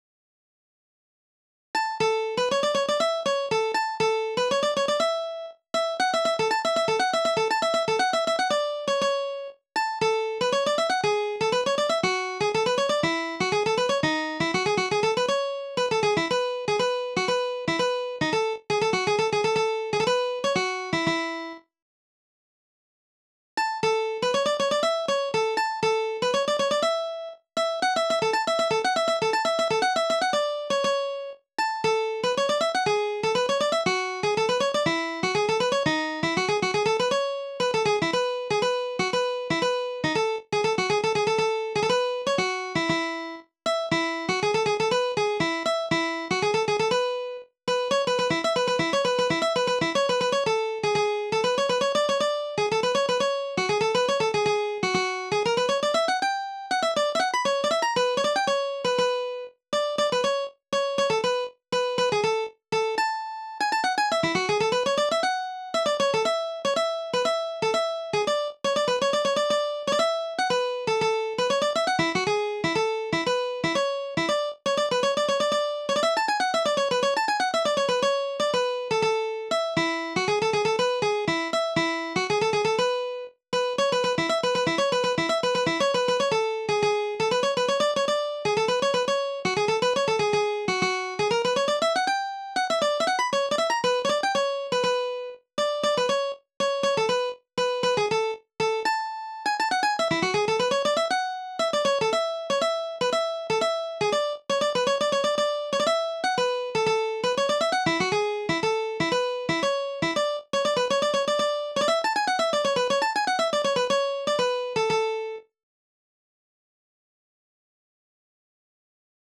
DIGITAL SHEET MUSIC - MANDOLIN SOLO